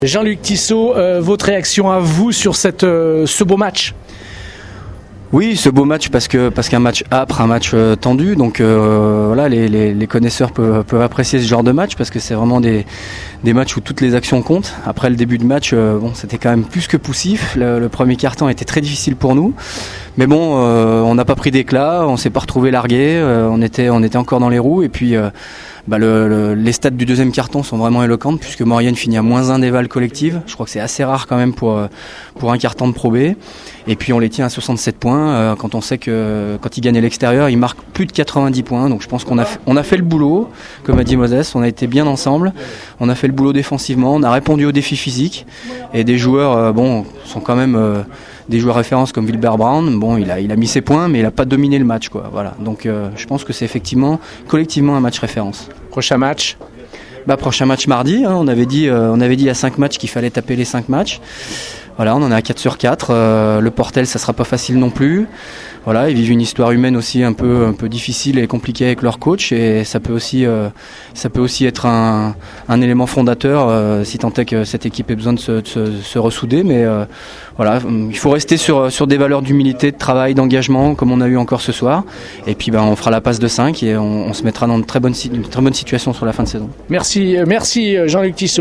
On écoute les réactions d’après-match